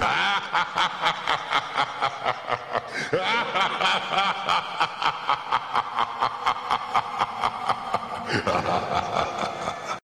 thriller cackle.wav